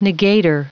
Prononciation du mot negator en anglais (fichier audio)
Prononciation du mot : negator